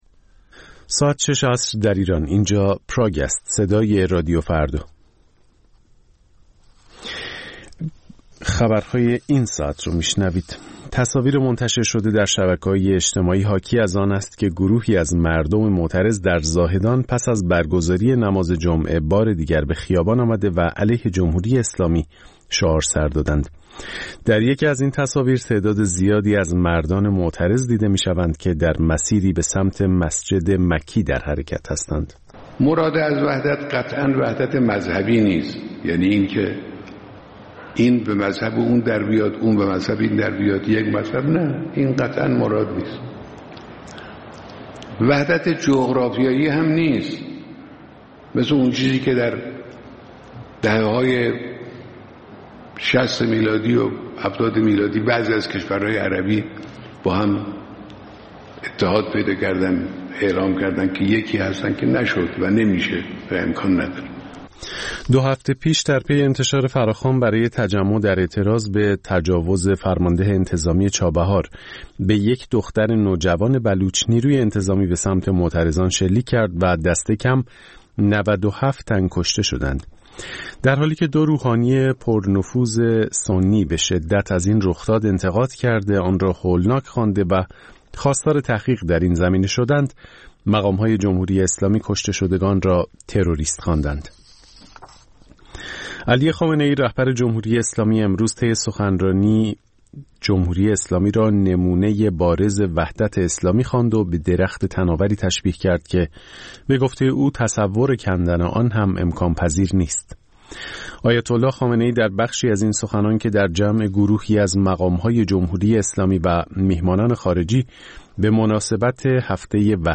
خبرها و گزارش‌ها ۱۸:۰۰